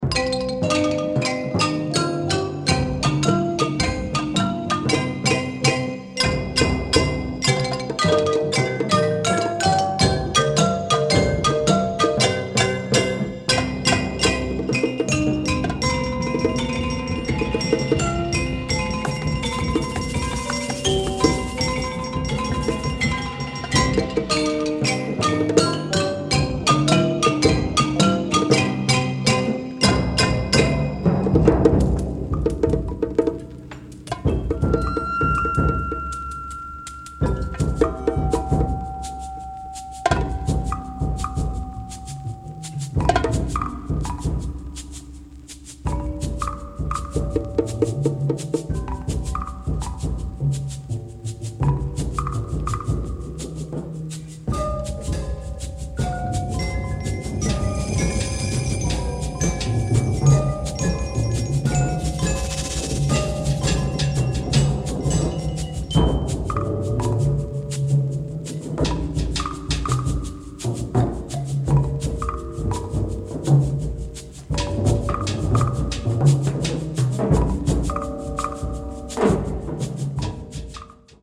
percussion band